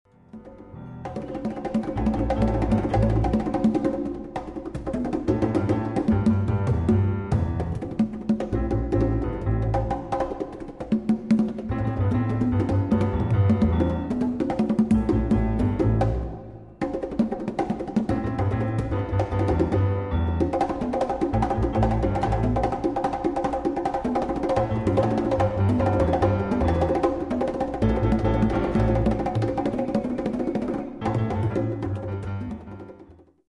Percussion
Piano
Bass